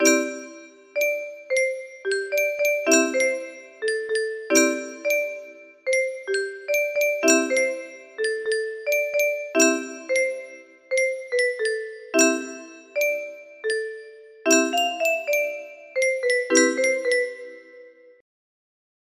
음악수행 music box melody
Grand Illusions 30 (F scale)